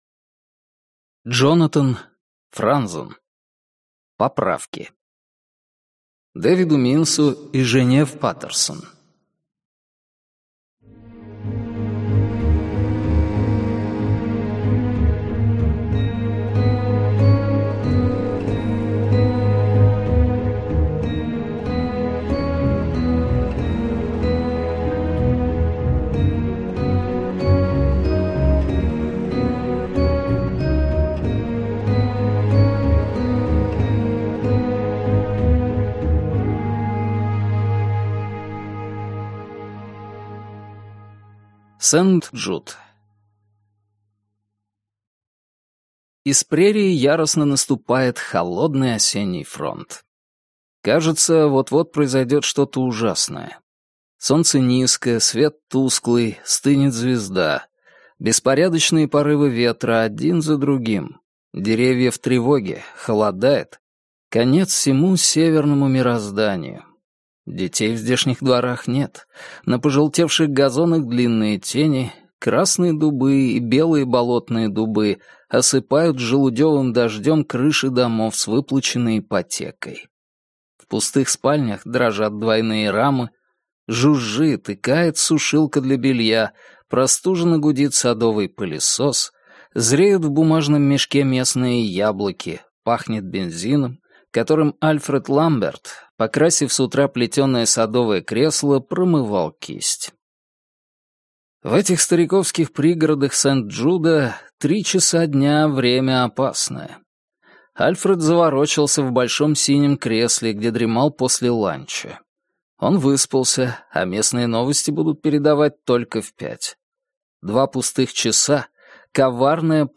Аудиокнига Поправки | Библиотека аудиокниг